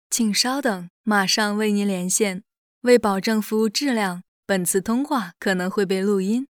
Female
Chinese (Mandarin)
Yng Adult (18-29), Adult (30-50)
Phone Greetings / On Hold
I.V.R / On Hold